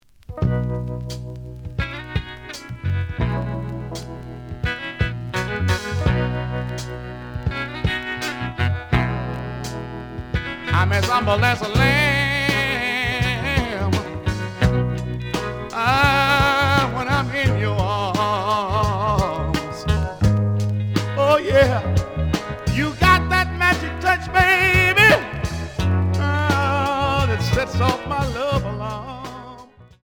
試聴は実際のレコードから録音しています。
●Genre: Soul, 70's Soul
●Record Grading: VG+ (盤に若干の歪み。多少の傷はあるが、おおむね良好。プロモ盤。)